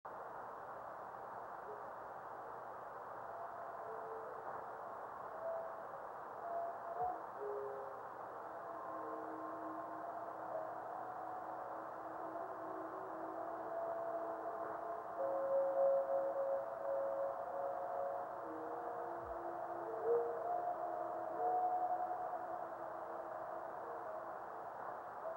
Below:  Radio spectrogram of the time of the meteor.  61.250 MHz reception above white line, 83.250 MHz below white line.